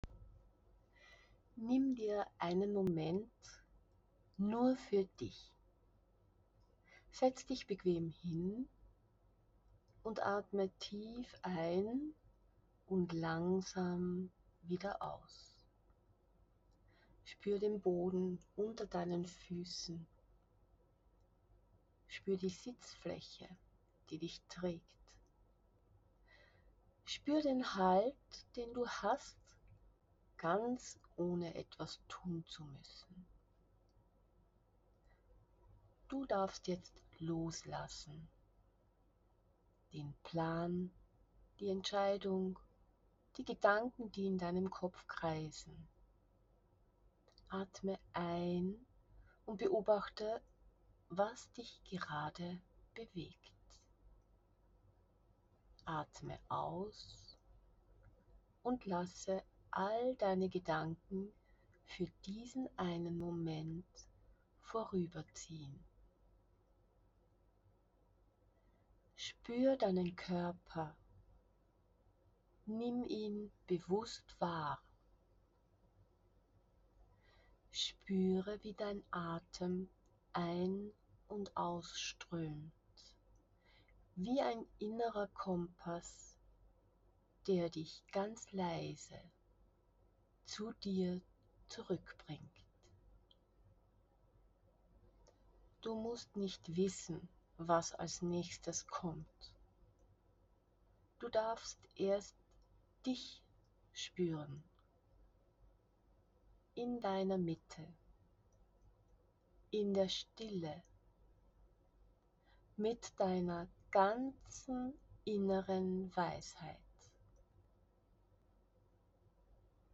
Eine kurze, geführte Pause, wenn du im Außen keinen Weg mehr siehst.
In dieser kurzen, geführten Meditation …
quiz-kompasstyp-meditation-innere-orientierung.mp3